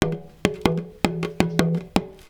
PERC 21.AI.wav